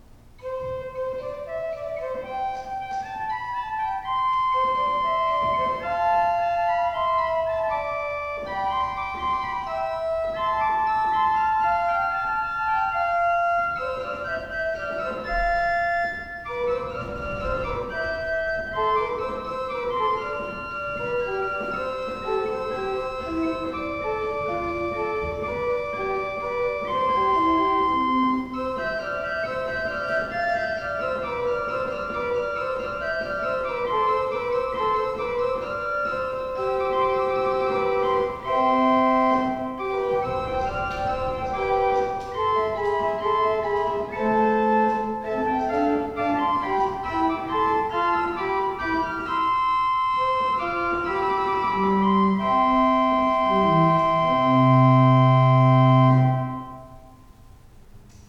Listen to an improvisation on the Principal 4' by clicking
Principal_4.wav